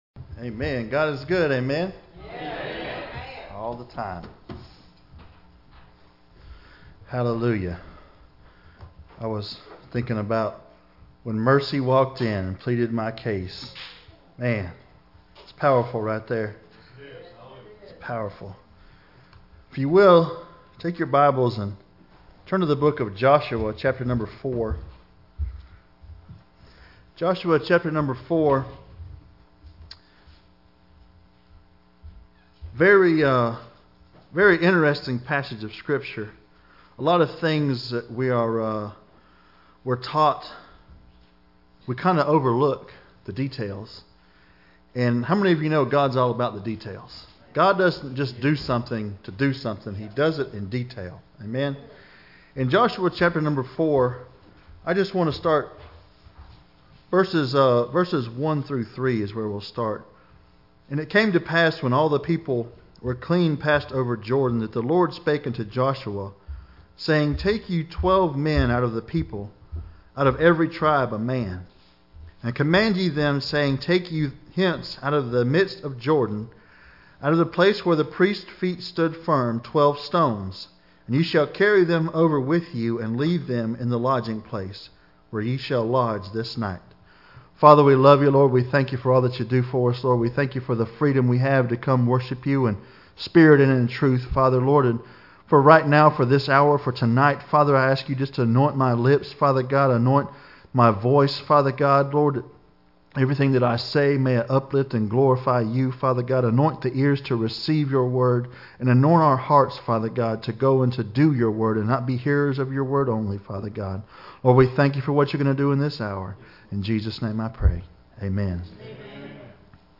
Thanks Giving Service
November 21, 2018 Thanks Giving Service Preacher: Everyone Passage: Psalms 136 Service Type: Wednesday Evening Services Topics: Thanks Giving Service « “Don’t Forget To Remember” Overcoming Darkness And Fear »